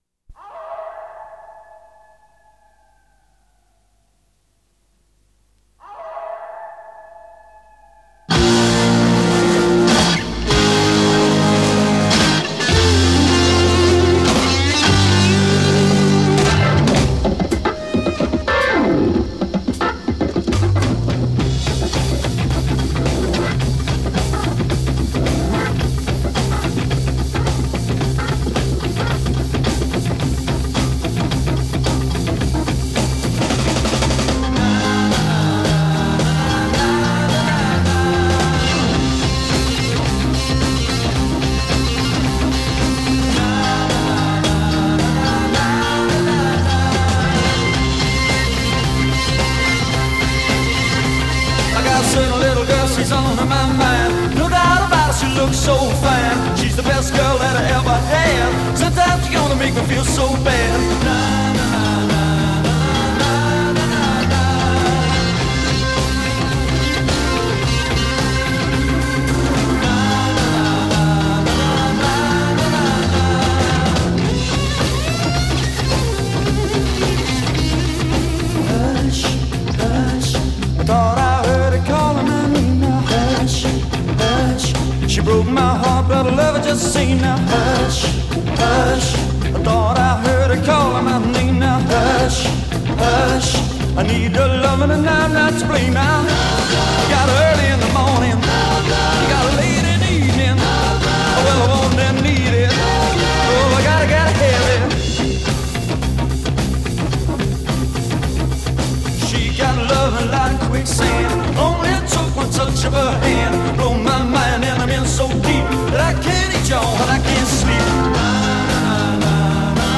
Recorded 11 May 1968 at Pye Recording Studios, London.
B Verse 31   extended organ solo (two performances combined)
Coda 4   Refrain material metrically augmented a